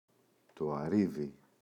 αρίδι, το [a’riði] – ΔΠΗ